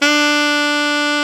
SAX A.FF D07.wav